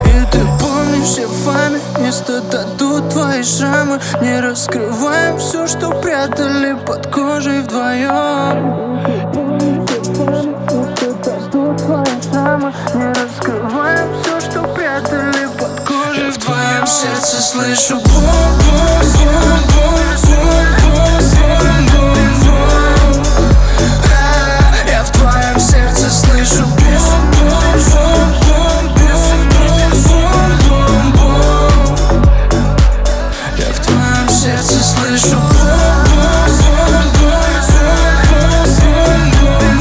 • Качество: 320, Stereo
лирика
Хип-хоп
клубнячок